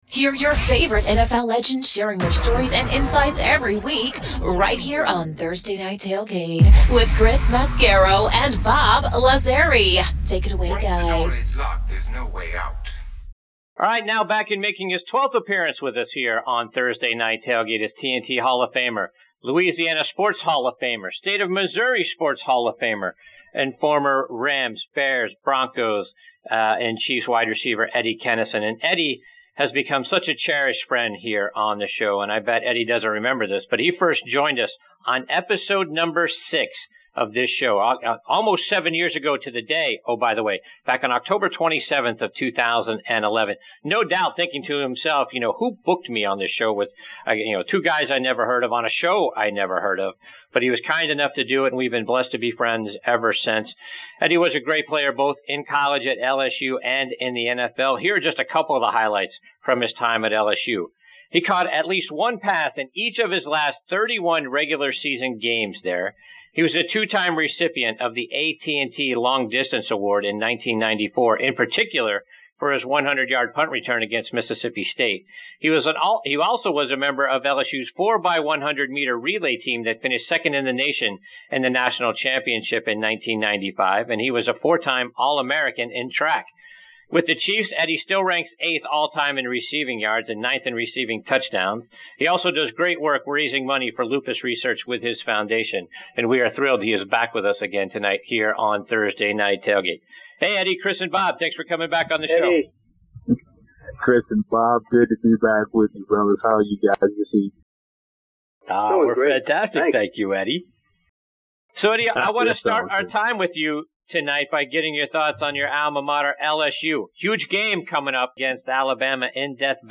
Eddie Kennison, former LSU, Rams & Chiefs WR, Joins Us on this Segment of Thursday Night Tailgate NFL Podcast